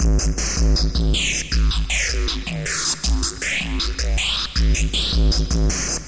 电击或静电
Tag: 电刑 电击 电力 电击枪 电电流 休克 OWI 泰瑟枪